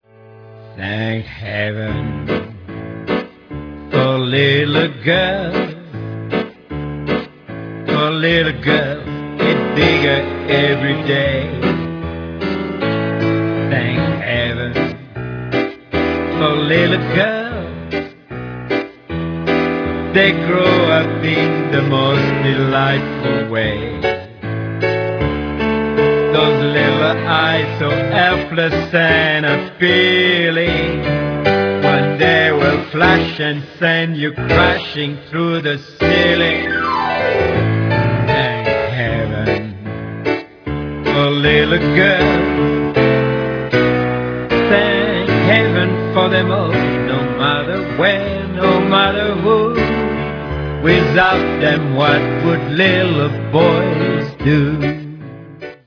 Special Guest Vocals